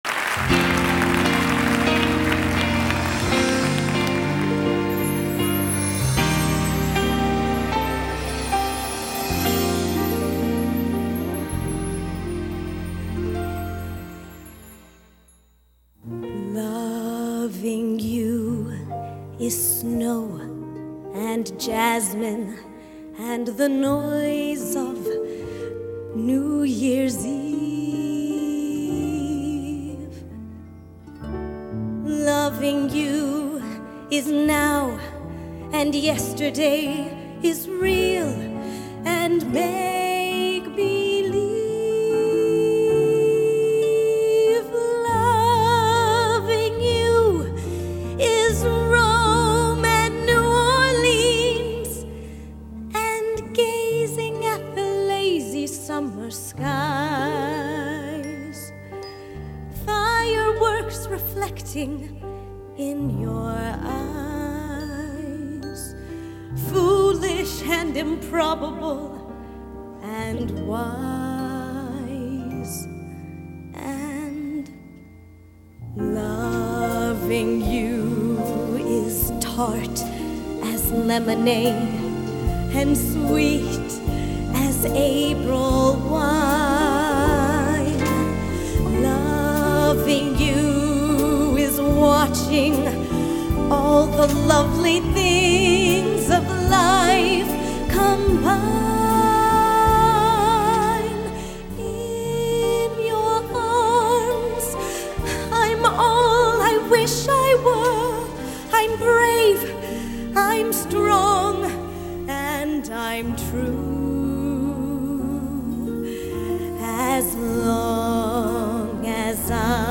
Soundtrack   Composer